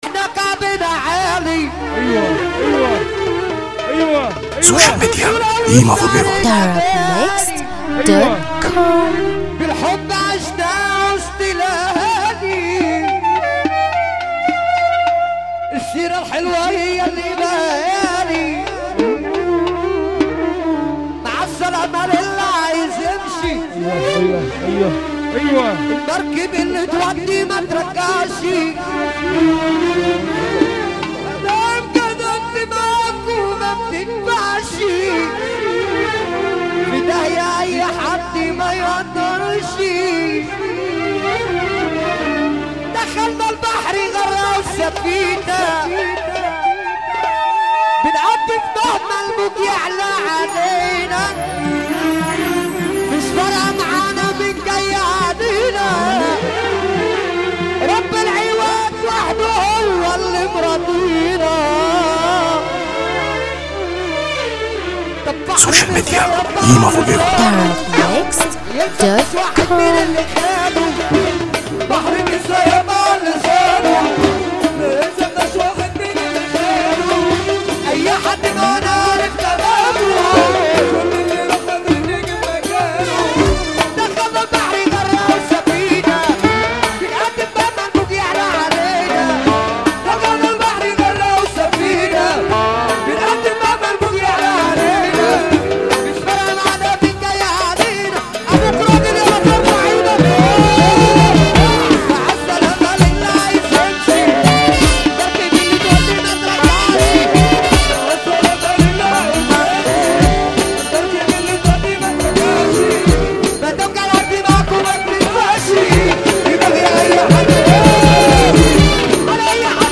موال
حزينة جدا